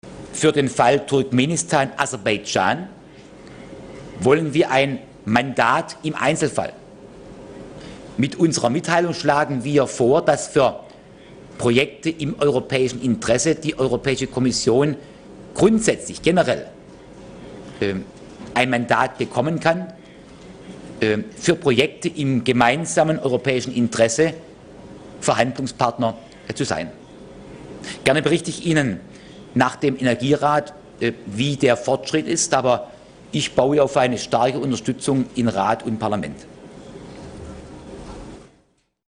Öttingeriň metbugat konferensiýasyndaky çykyşyndan, Brýussel, 7-nji sentýabr